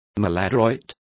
Complete with pronunciation of the translation of maladroit.